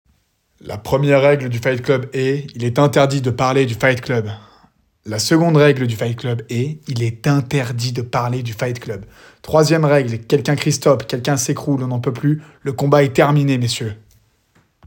Bandes-son
24 - 30 ans